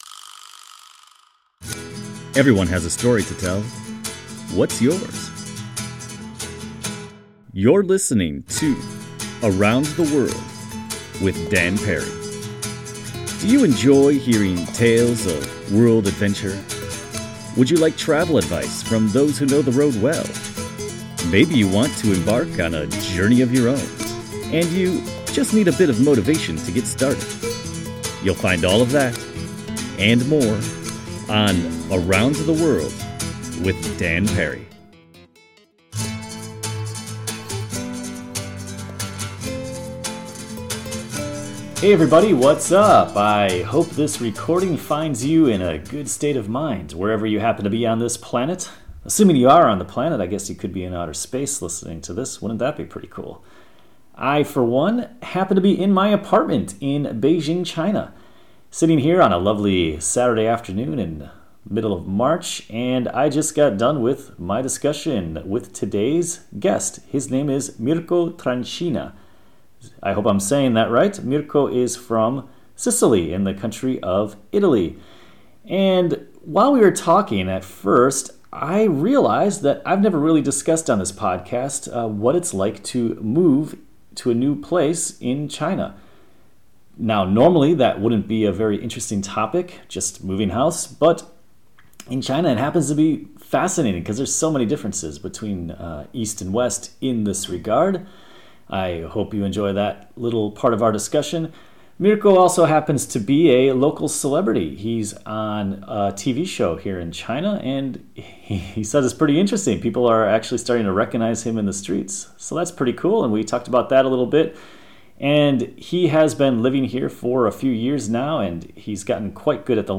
We had an awesome conversation; I hope you enjoy it. Download this Episode (right-click and choose “save as”) Show Notes: Check out this video of flooding in Beijing: We talked about the new (and giant) wave of bicycle-sharing companies in China.